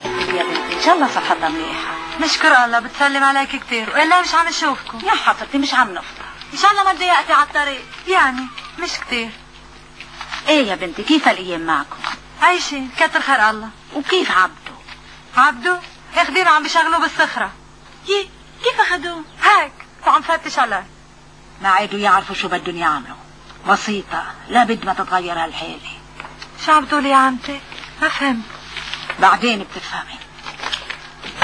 dialogue between salwa and  Fairouz , about the tirany of the ottoman criminals who were commiting crimes while occupying Lebanon and the rest of the East , keeping it in the darkness and the ignorance, when this film "Safar Barlek" was produced the Turkish government attacked it  , and managed to stop it from showing in many arabic nations unfortunately , but whatever the turcs or some ignorant arab minds think , the truth still is that Lebanon remained , the east got Free and culture and arts are making a new heritage in this region .